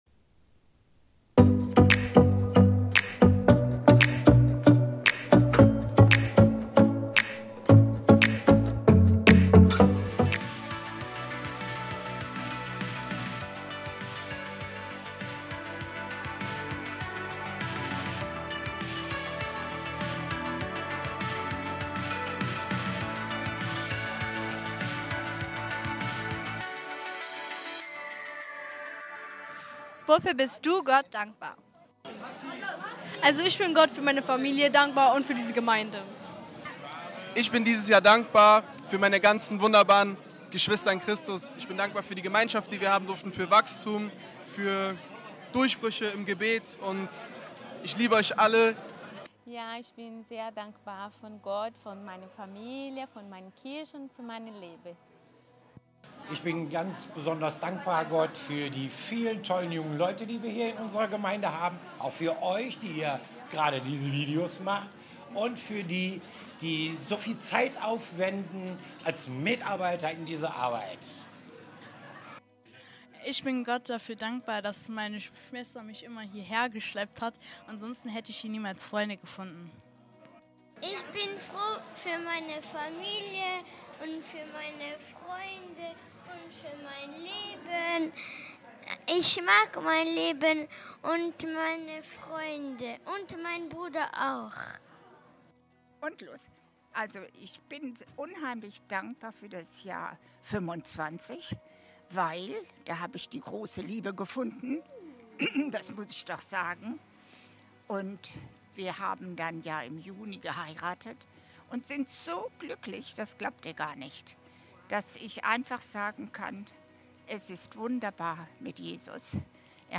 Live-Gottesdienst aus der Life Kirche Langenfeld.
Sonntaggottesdienst